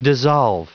Prononciation du mot dissolve en anglais (fichier audio)
Prononciation du mot : dissolve